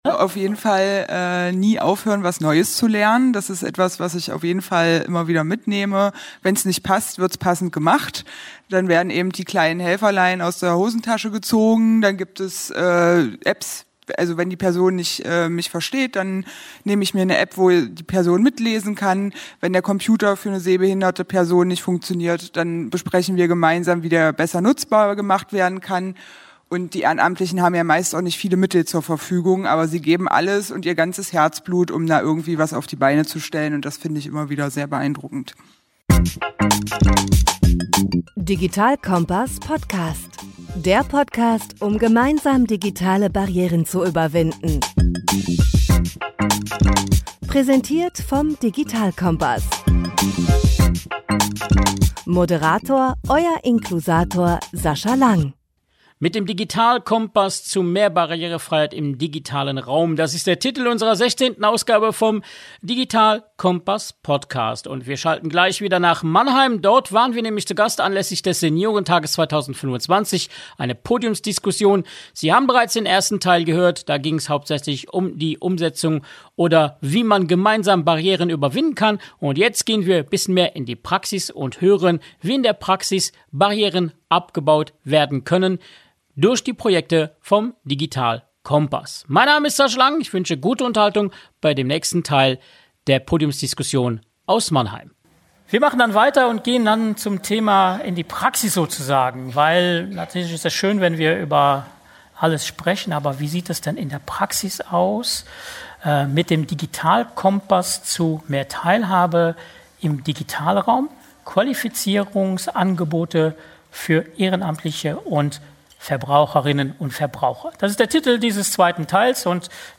Folge 16 des Digital-Kompass-Podcasts ist der zweite Teil der Aufzeichnung der Podiumsdiskussion vom Deutschen Seniorentag.